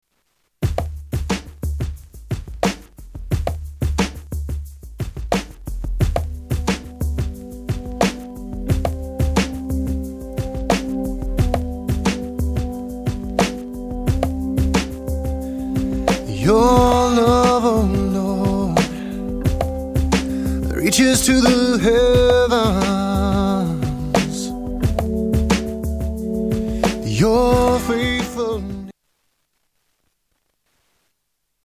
STYLE: Rock
worship oriented